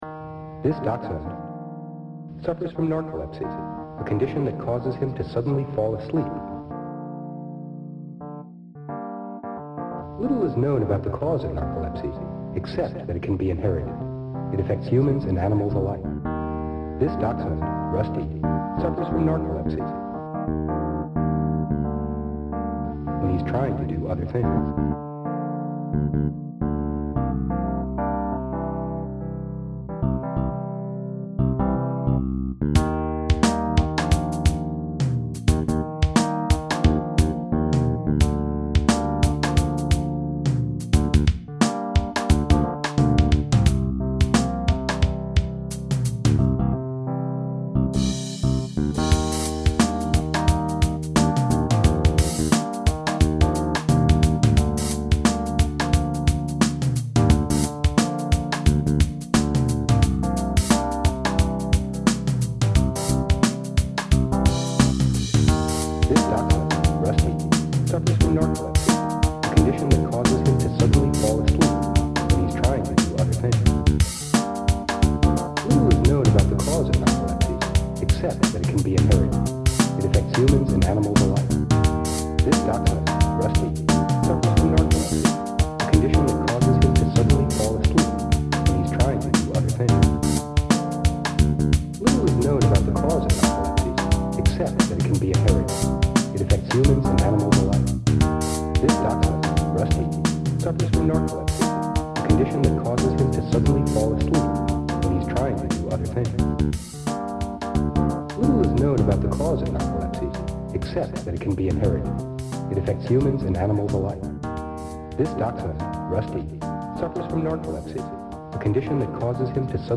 sleepy_dog.mp3